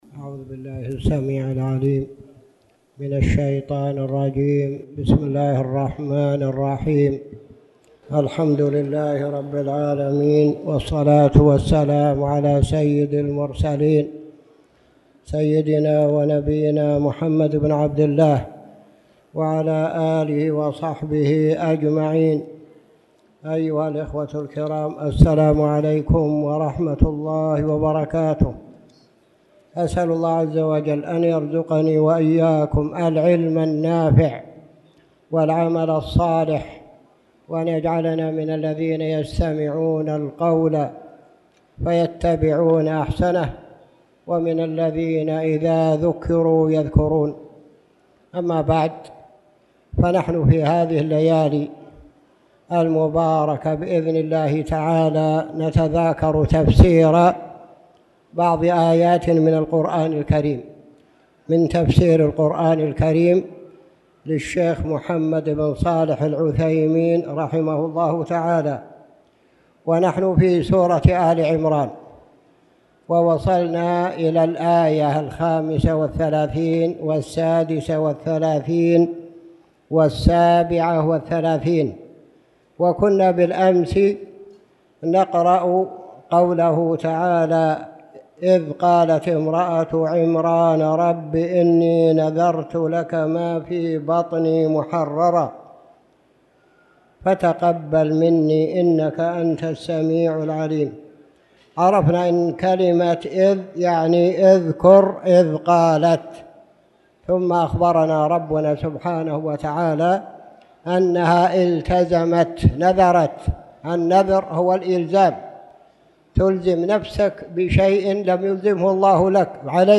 تاريخ النشر ١١ جمادى الأولى ١٤٣٨ هـ المكان: المسجد الحرام الشيخ